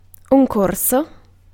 Vaihtoehtoiset kirjoitusmuodot (vanhentunut) subiect Synonyymit matter topic underbring break purpose question testee item evaluatee Ääntäminen : IPA : [ˈsʌb.dʒɛkt] Tuntematon aksentti: IPA : /səb.ˈdʒɛkt/